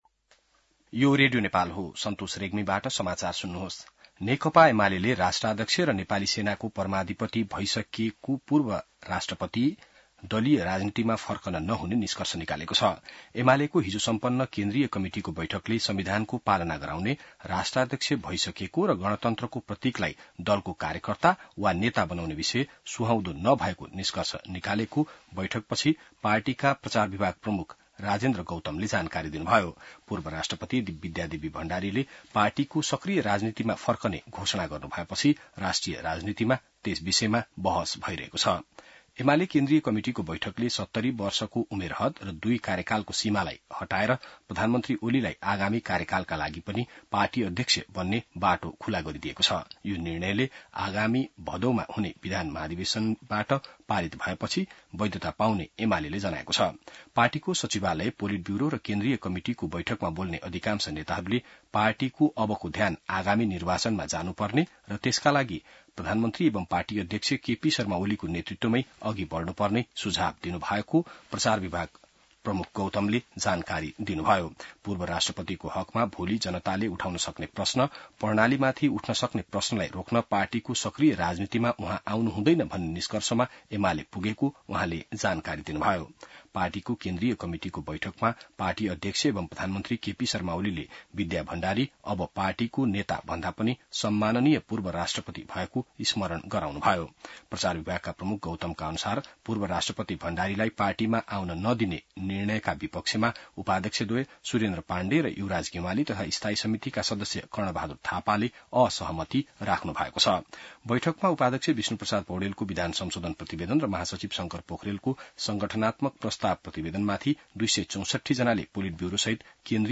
बिहान ६ बजेको नेपाली समाचार : ७ साउन , २०८२